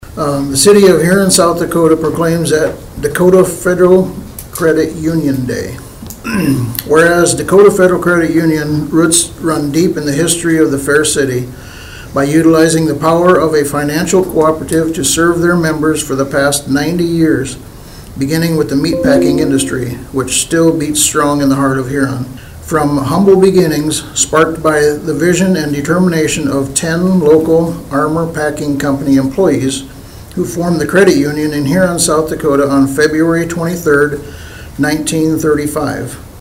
During Monday nights Huron City commission meeting Huron Mayor Mark Robish read a proclamation for Dakotaland Federal Credit Union Day.